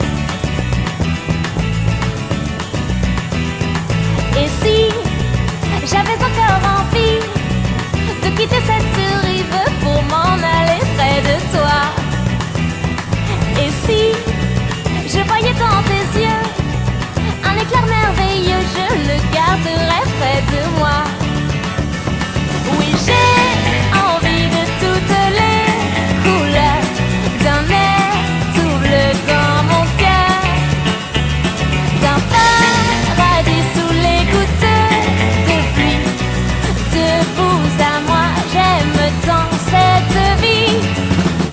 • Качество: 158, Stereo
веселые